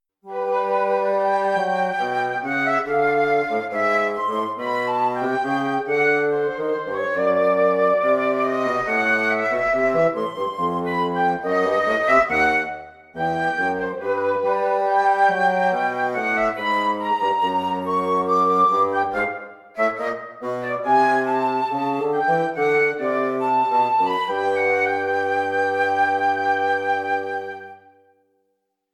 Música clàssica i contemporània
Ab-dictat-harmonic-classica-cobla-audio-24-06.mp3